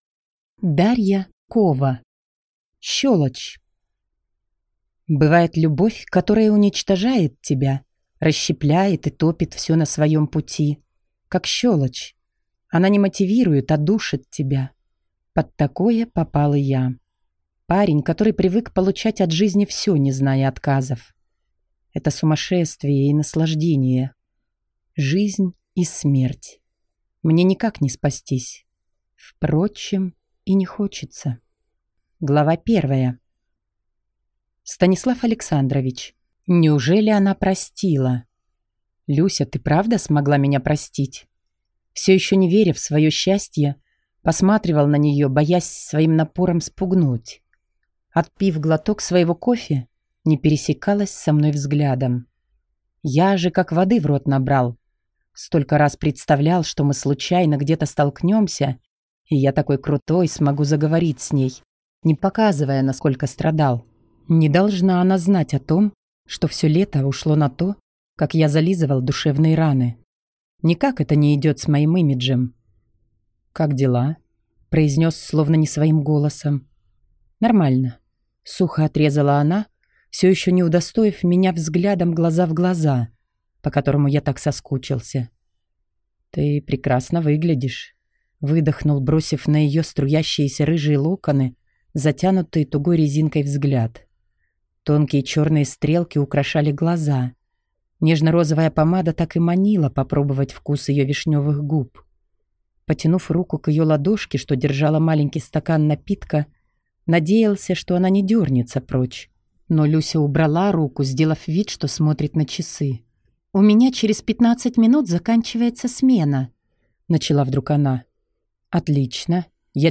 Аудиокнига Щелочь | Библиотека аудиокниг
Прослушать и бесплатно скачать фрагмент аудиокниги